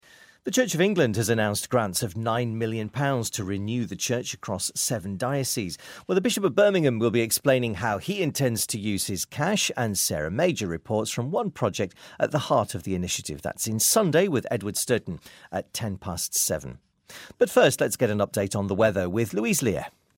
Radio 4 Continuity Announcement
As broadcast on BBC Radio 4, Sunday 15th January, 2017.